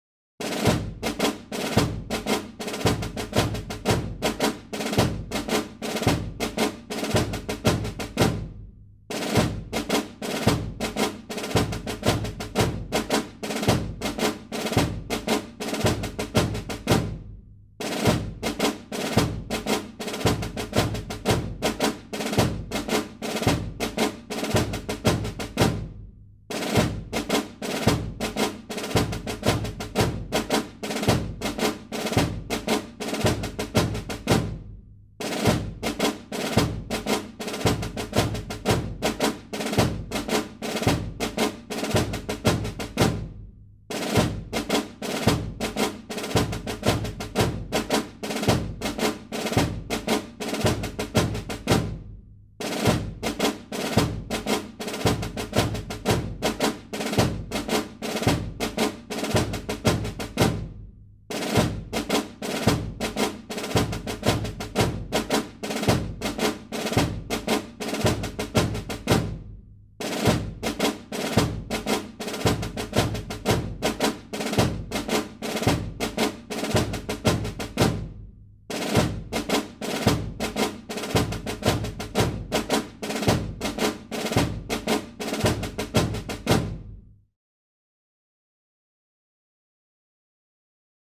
Drum - Cadence B.ogg to featured sound status.
Drum_-_Cadence_B.ogg.mp3